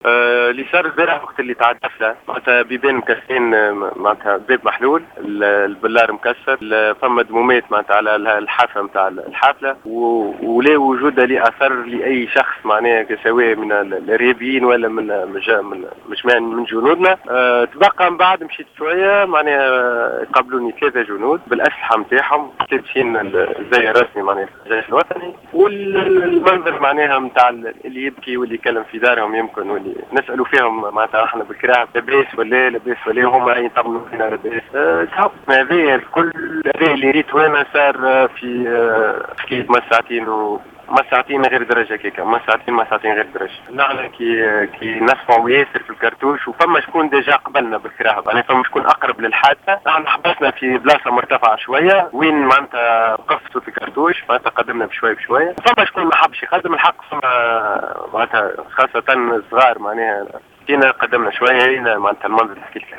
تحدث شاهد عيان على حاثة الهجوم الإرهابي على حافلة الجيش الوطني يوم أمس بمنطقة نبر من ولاية الكاف، لجوهرة أف أم عما شاهده أثناء مروره بالمنطقة.